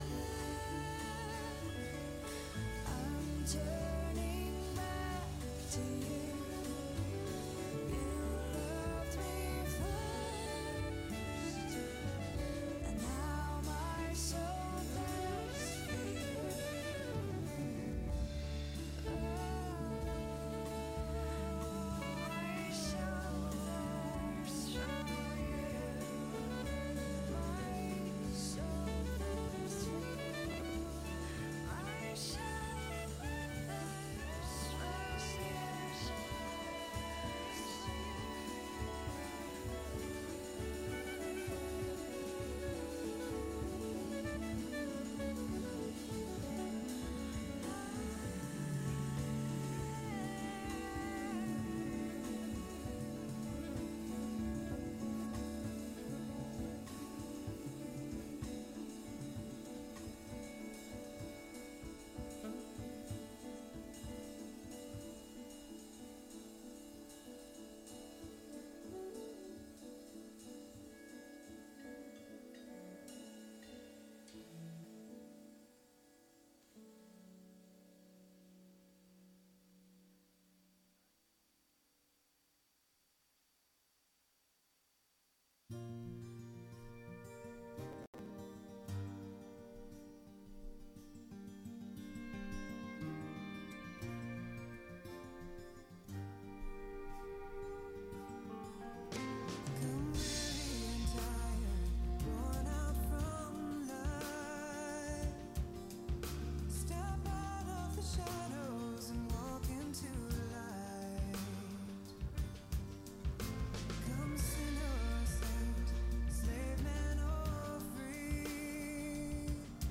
May 26 Worship Audio – Full Service